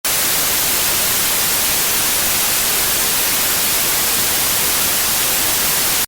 Tv Static Loud
TV Static Loud.mp3